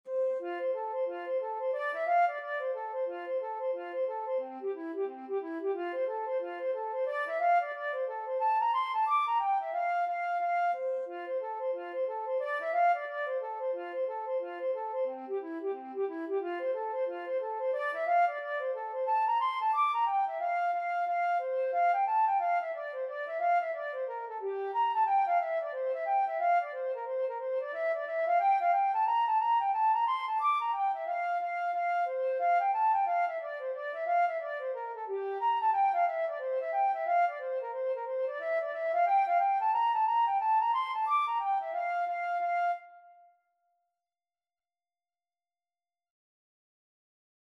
4/4 (View more 4/4 Music)
F major (Sounding Pitch) (View more F major Music for Flute )
Flute  (View more Intermediate Flute Music)
Traditional (View more Traditional Flute Music)